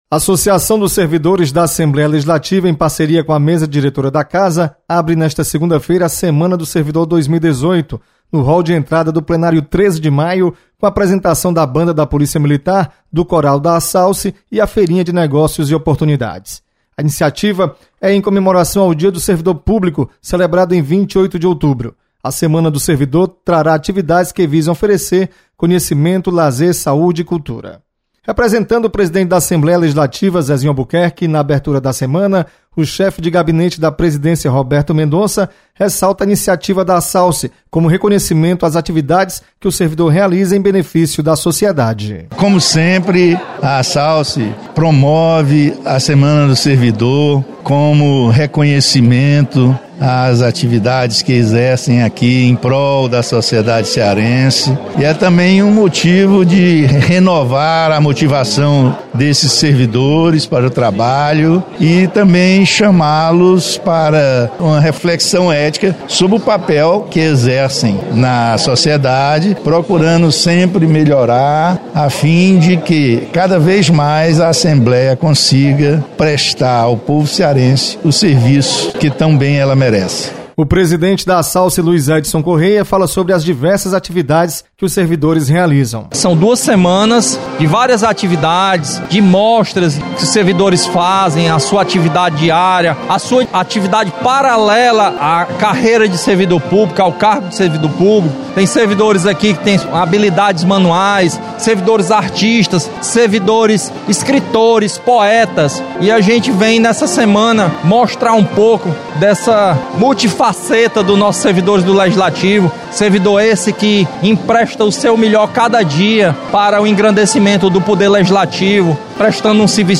Assalce promove Semana dos Servidor. Repórter